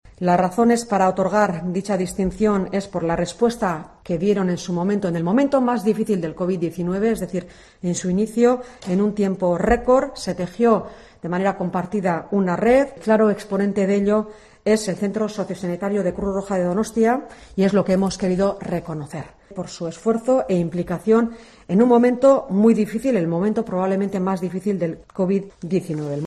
Eider Mendoza, portavoz de la Diputación de Gipuzkoa